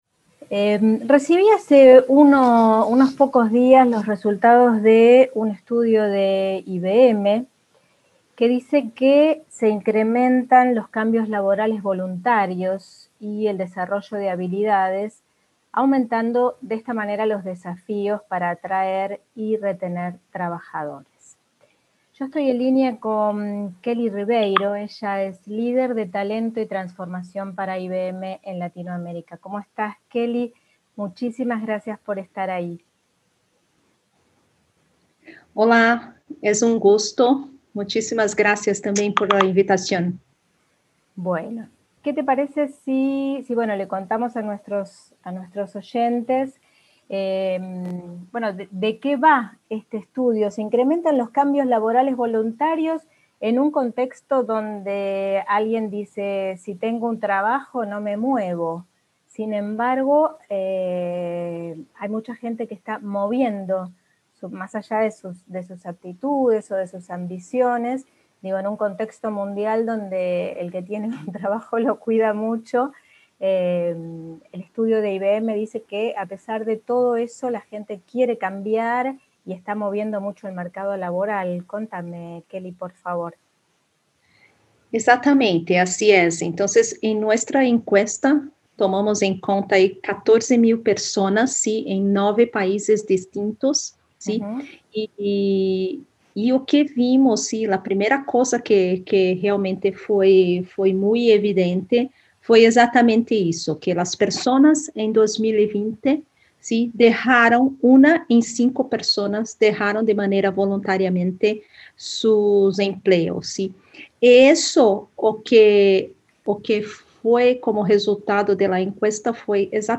entrevista-IBM.mp3